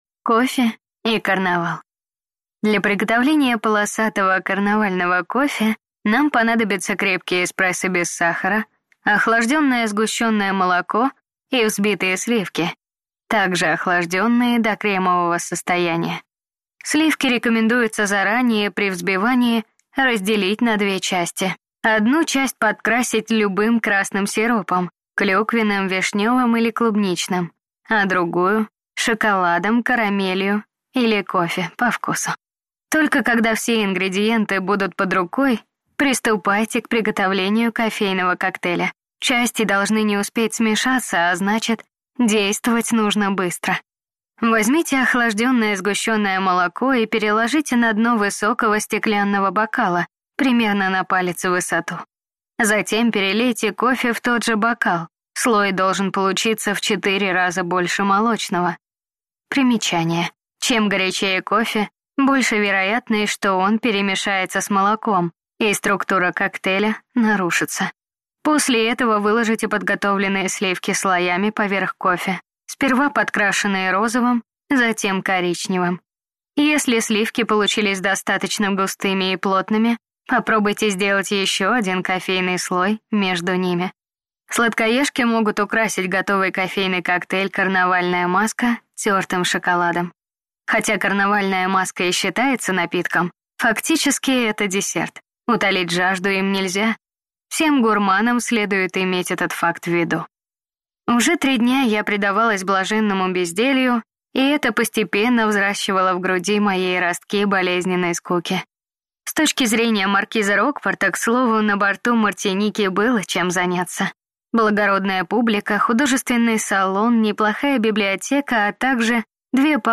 Аудиокнига Кофе и карнавал | Библиотека аудиокниг
Прослушать и бесплатно скачать фрагмент аудиокниги